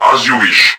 I wanted Volkov & Chitzkoi voices, but wasn't sure what to use, so i decided to take the C&C Remastered RA1 voice lines and triplex them, with some adjustments, to make them cybornetic sounding.